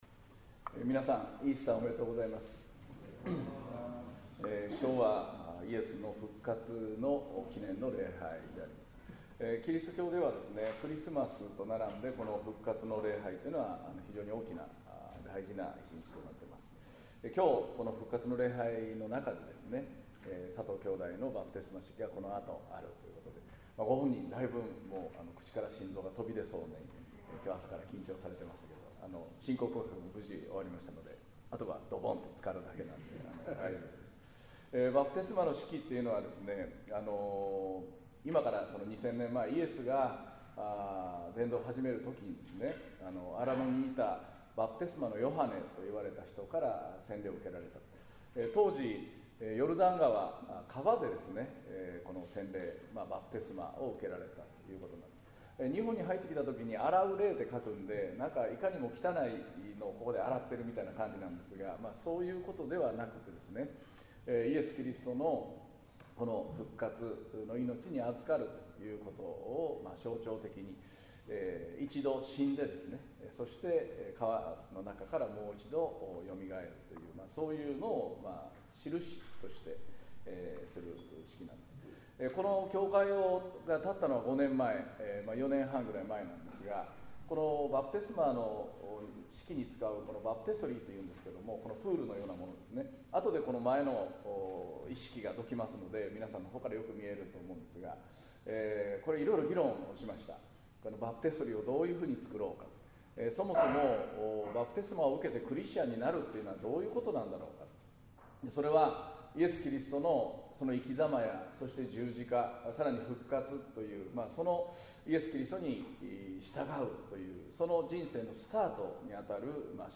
2019年4月21日（日）イースター礼拝 宣教題「あなたをあきらめない―イエスは復活された」 | 東八幡キリスト教会